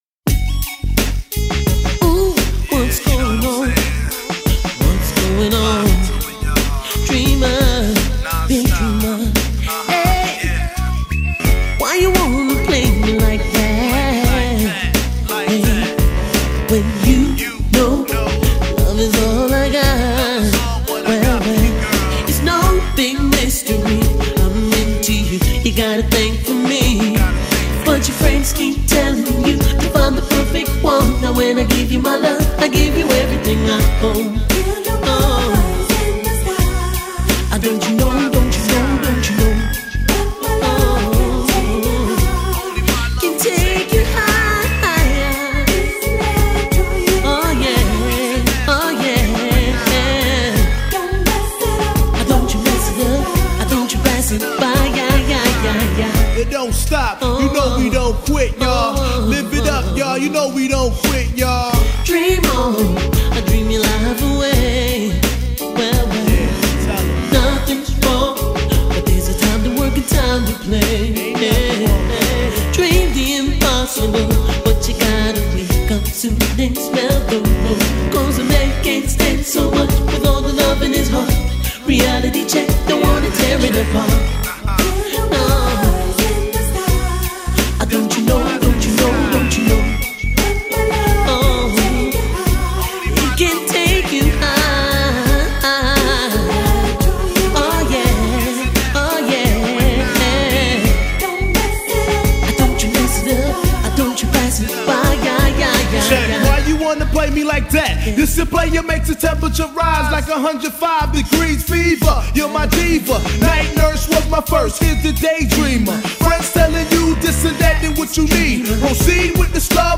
Dreamer – Reality Check Rap